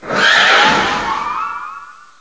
direct_sound_samples / cries